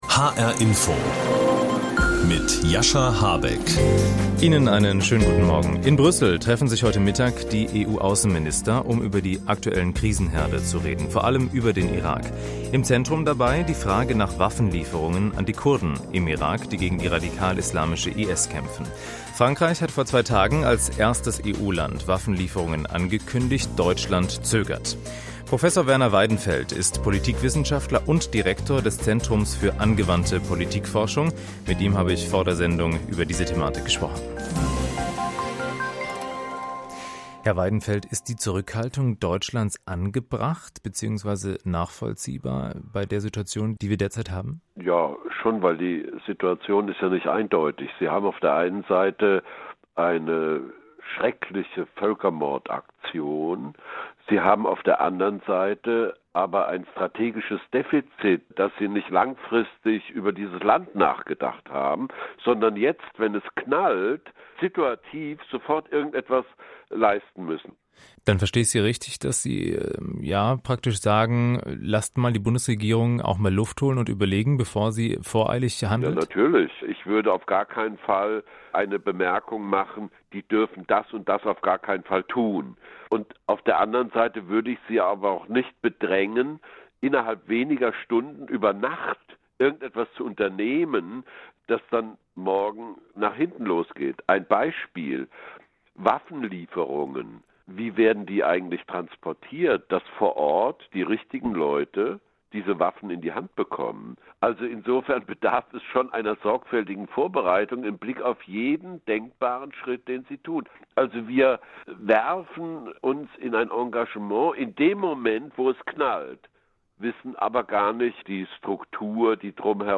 Interview mit Prof. Dr. Werner Weidenfeld